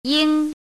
“英”读音
yīng
国际音标：jiŋ˥
yīng.mp3